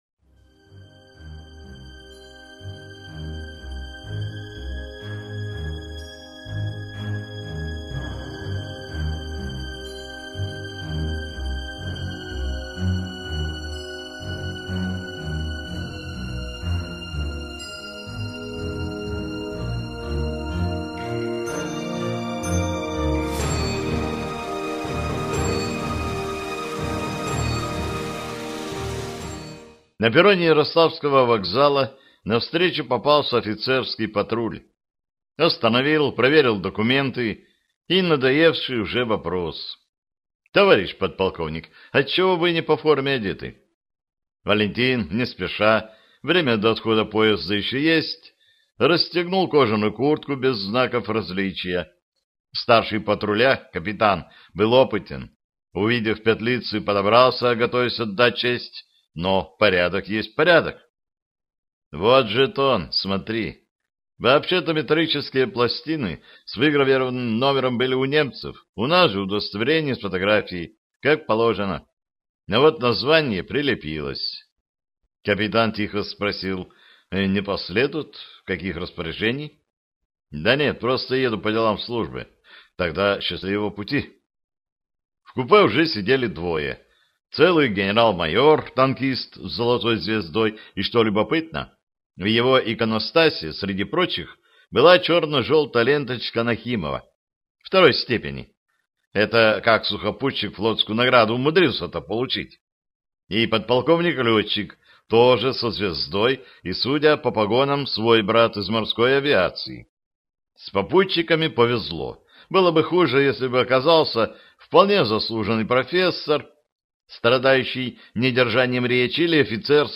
Аудиокнига Алеет восток | Библиотека аудиокниг